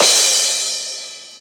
Crash Cym 1.wav